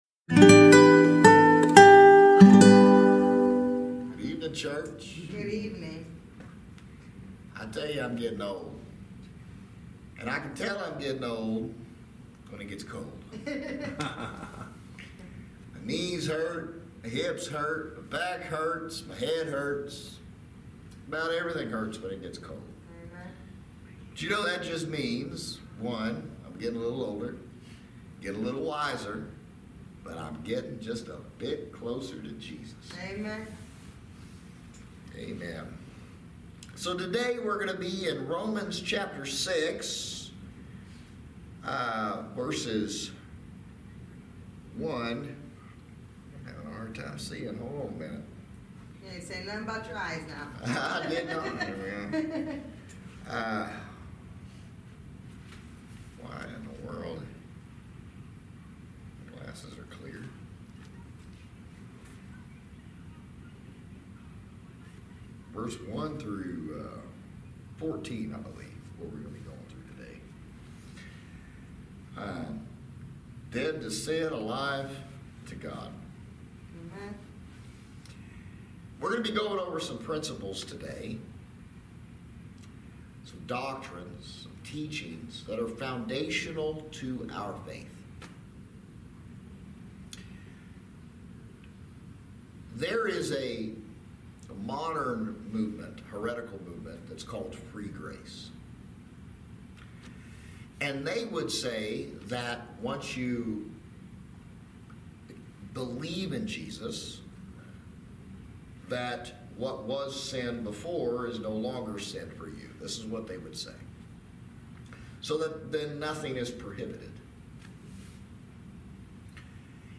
Service Type: Thirsty Thursday Midweek Teaching